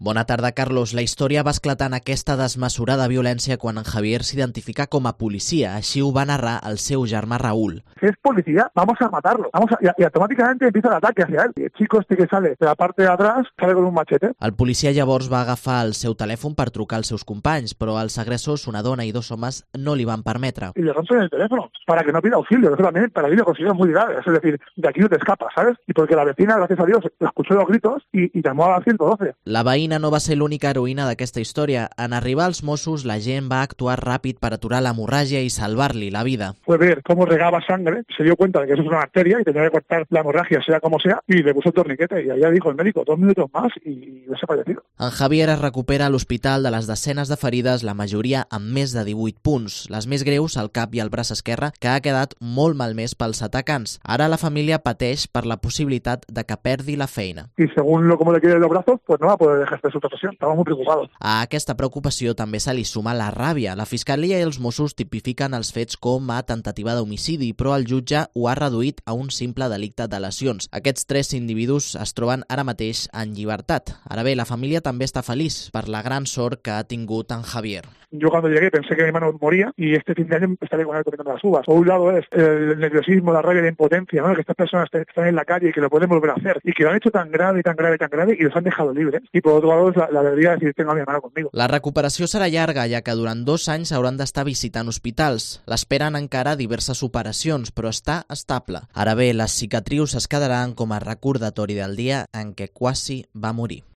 crónica del policía local apaleado por okupas